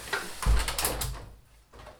• closing an insulating glass window.wav
Recorded with a Tascam DR 40
closing_an_insulating_glass_window_l2E.wav